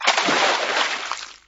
tubsplash2.wav